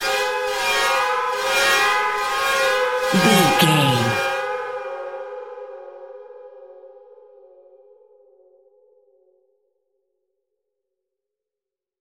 In-crescendo
Atonal
ominous
suspense
haunting
eerie
synth
ambience
pads
eletronic